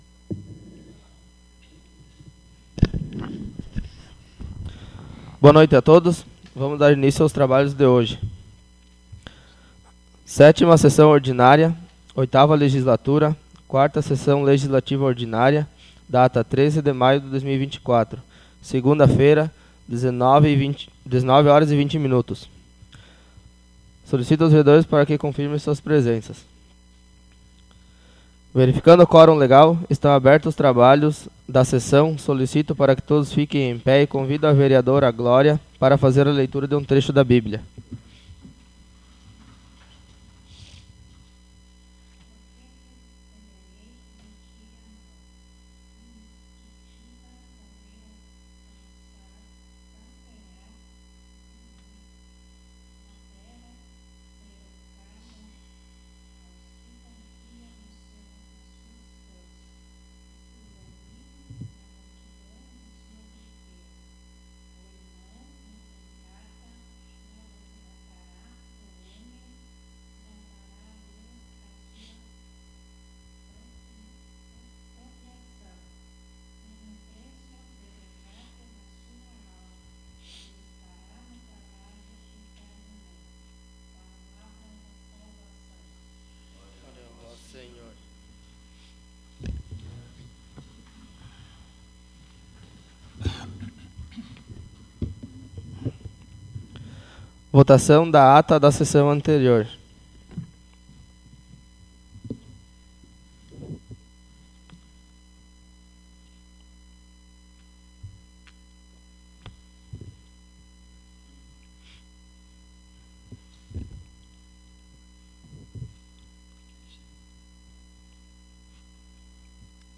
Audio da 7ª Sessão Ordinária - 13.05.24